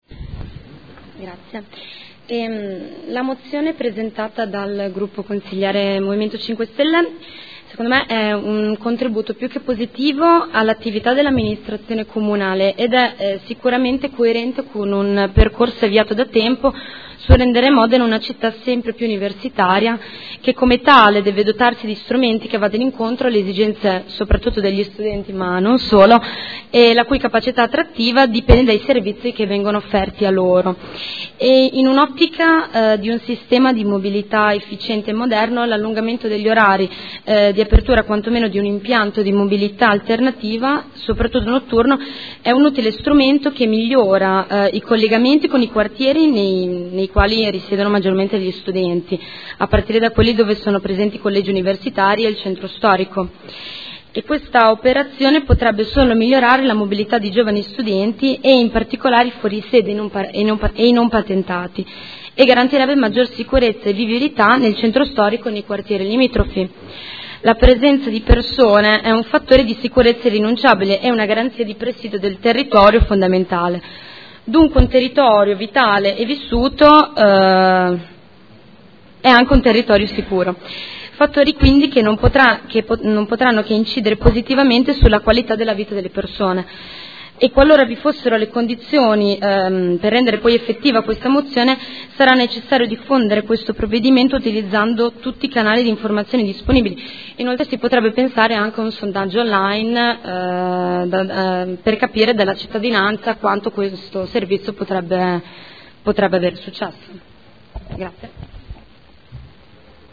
Federica Venturelli — Sito Audio Consiglio Comunale